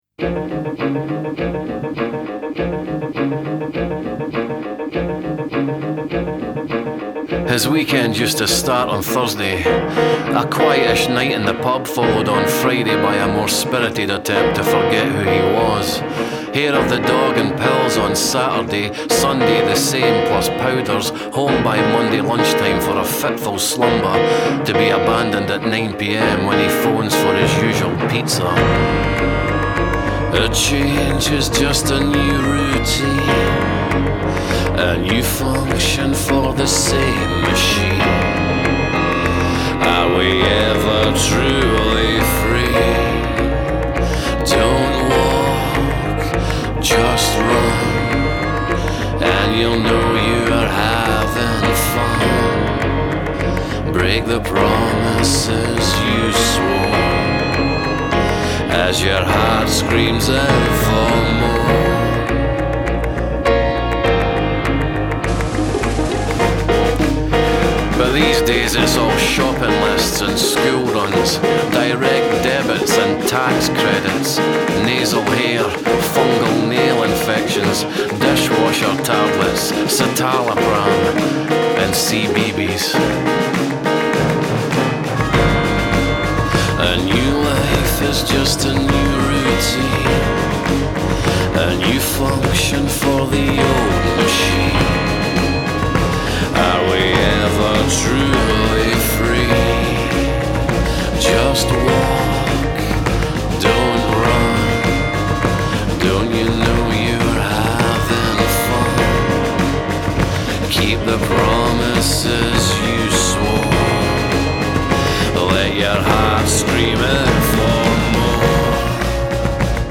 singing and doing spoken word
jazz-leaning instrumentals